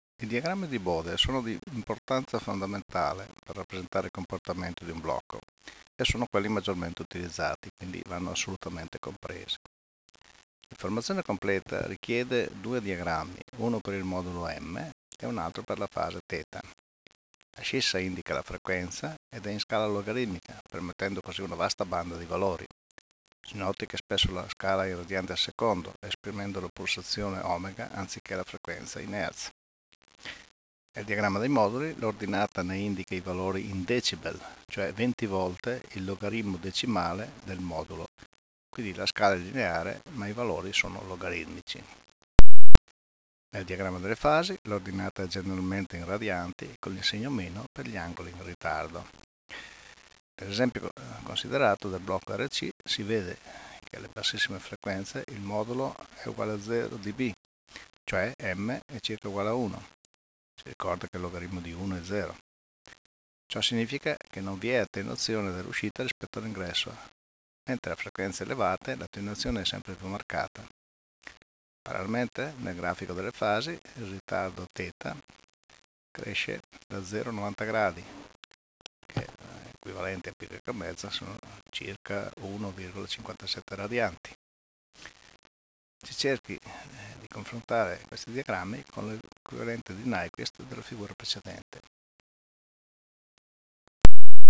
[commento audio]